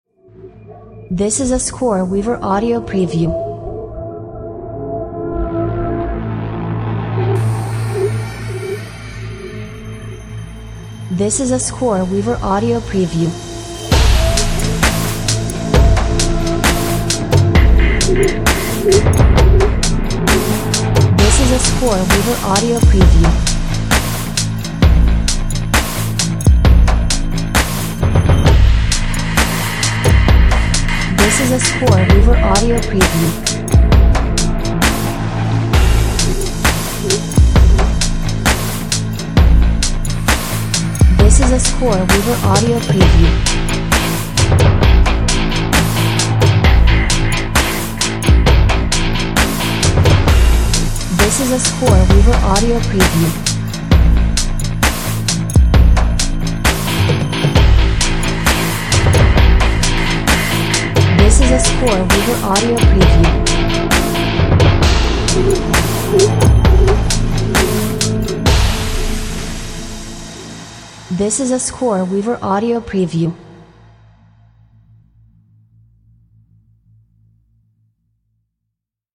Suspenseful guitars and a dubstep beat!
Eery Reality TV tension!